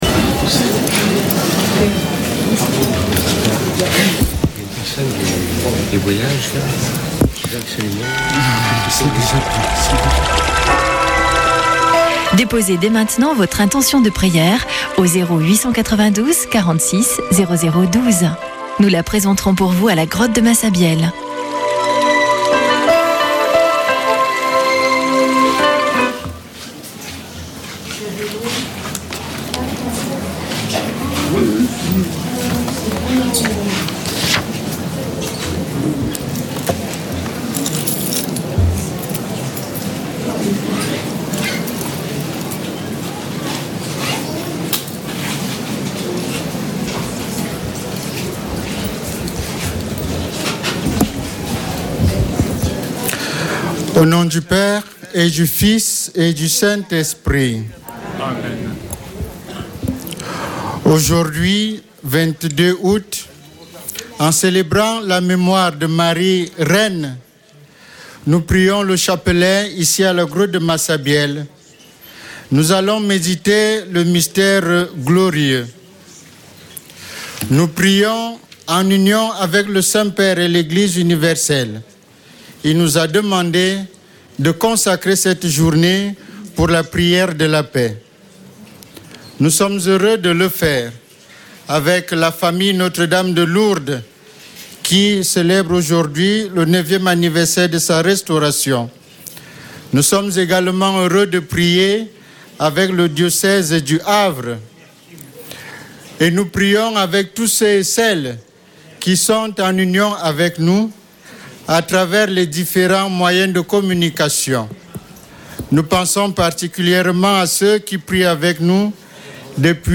Chapelet de Lourdes du 22 août
Une émission présentée par Chapelains de Lourdes